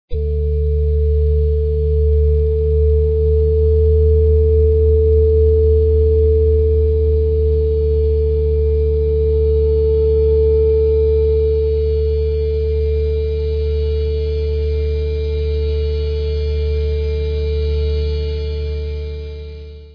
30 minutes of stoner rock -minus the greasy hair-